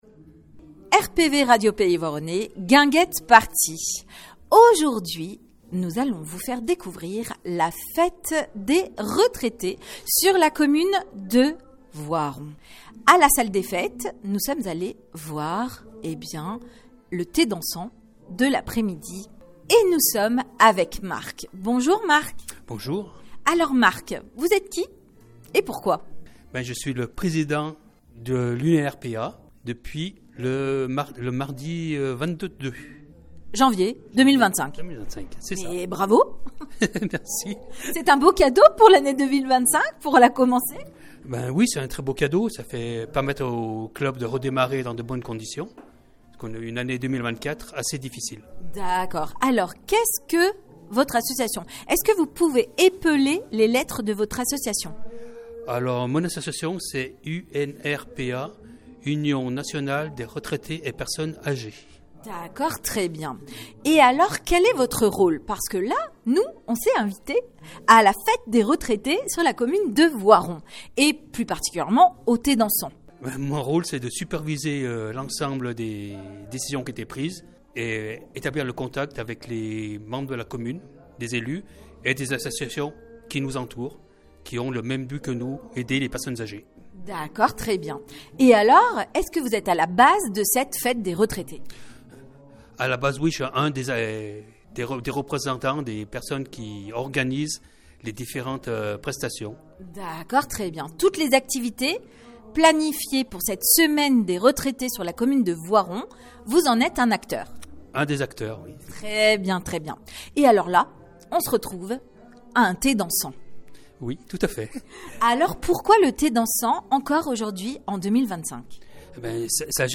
La Fête des Retraités Thé-Dansant À la Salle des fêtes de Voiron